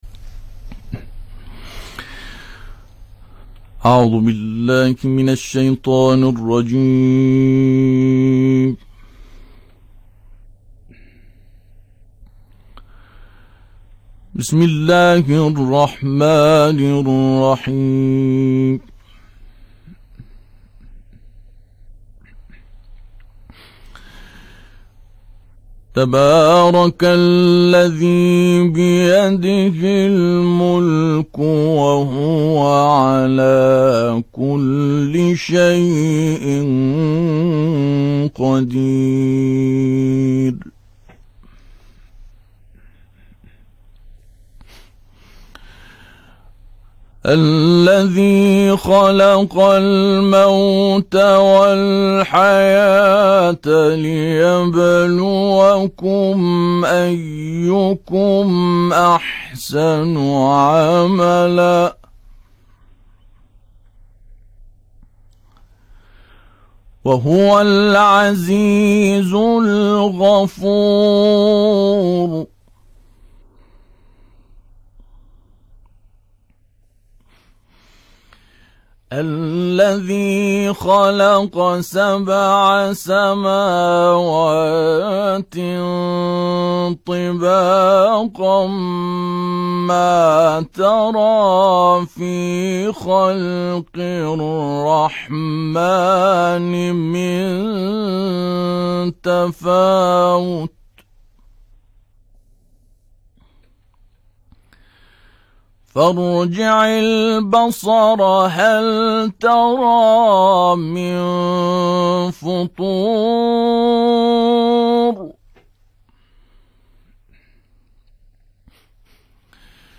جدیدترین تلاوت
با حضور در رادیو اهواز در موعد اذانگاهی ظهر امروز، به تلاوت آیات 1 تا 23 سوره مبارکه ملک پرداخت